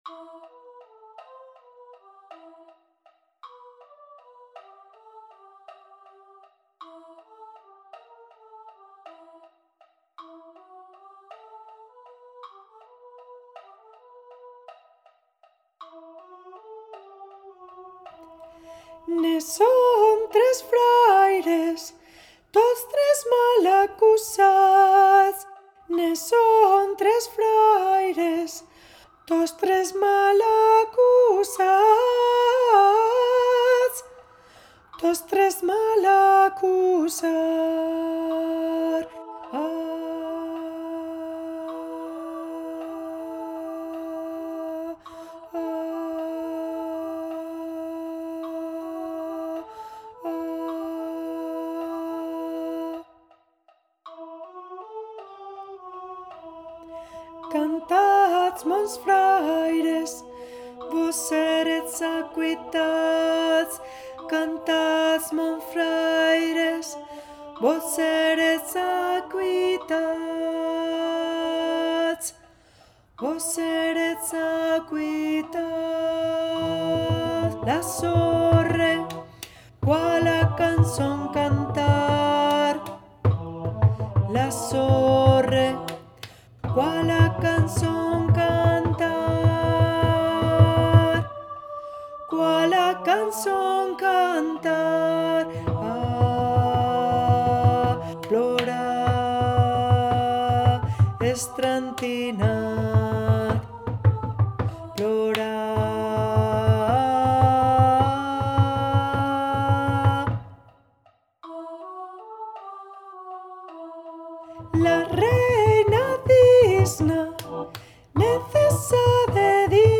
canción tradicional occitana
en arreglo para coro de voces iguales SMA.
coro SMA